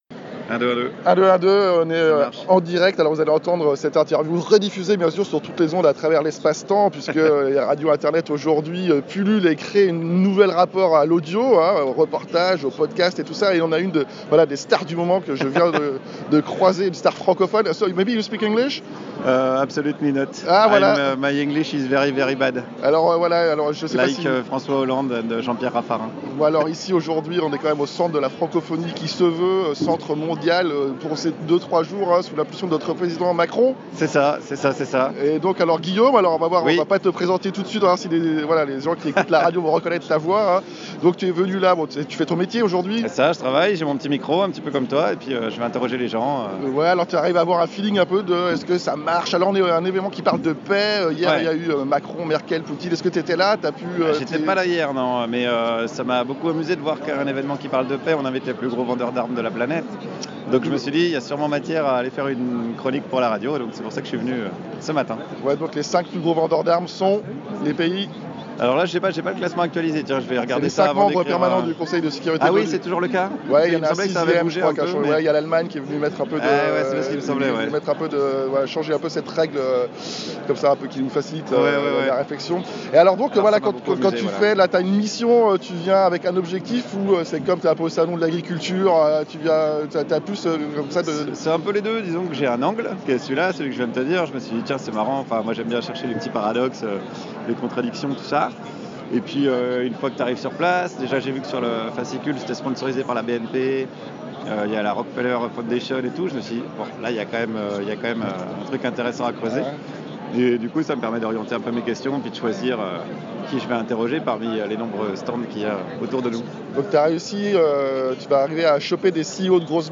Interview instinctif et disruptif de Guillaume Meurice au Forum de la Paix de Paris